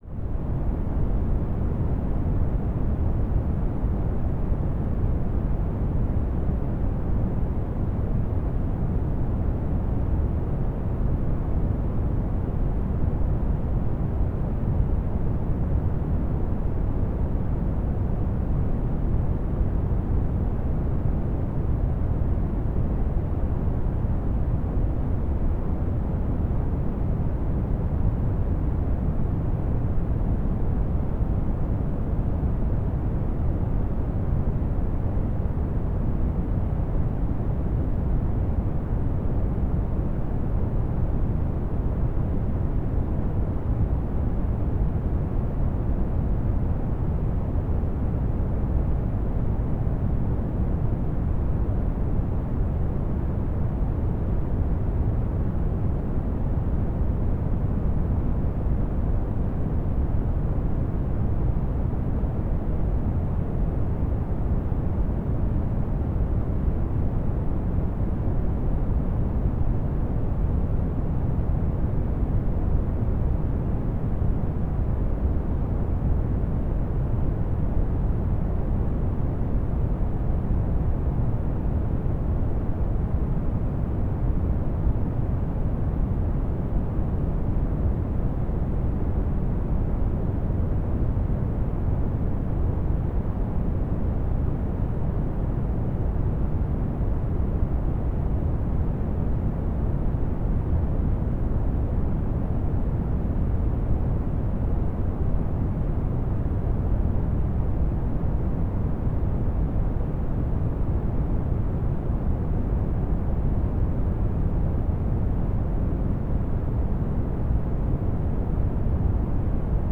03 - Bruit rouge profond.flac